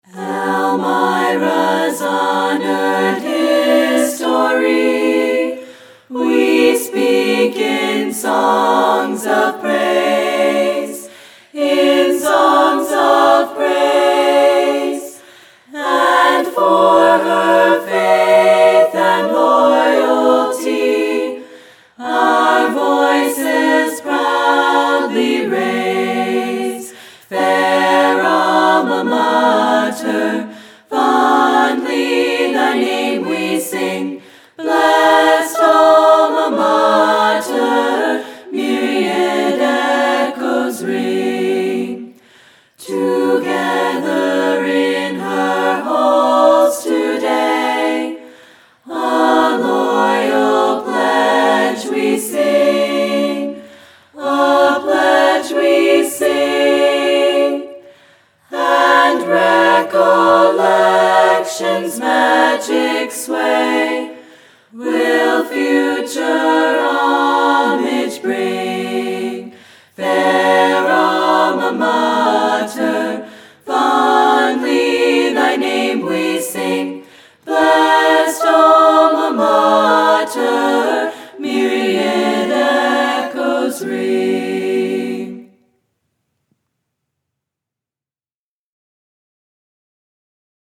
Click on the "Listen (MP3)" button to hear the song performed by the EC Chiclettes where available.